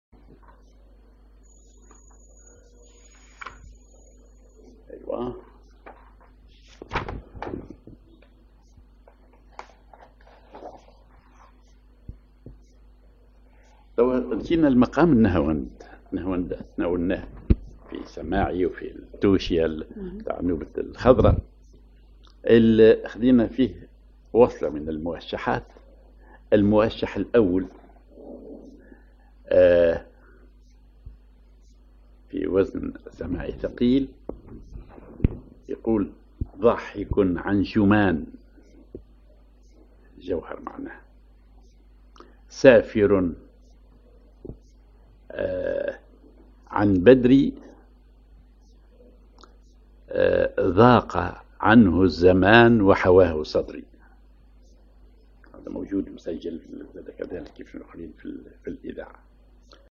Maqam ar نهاوند
Rhythm ar سماعي ثقيل
genre موشح